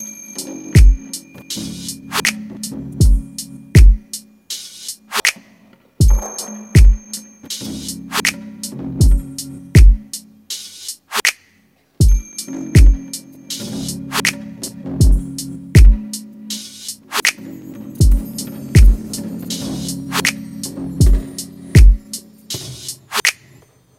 战斗机轰鸣声
描述：2018珠海航展歼10B推力矢量验证机飞行表演
标签： 战斗机 航展 轰鸣声 飞行表演
声道立体声